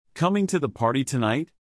It is often okay to skip certain sentence components (including verbs), use filler words and change your intonation to ask questions when you are conversing informally.
Conversational English: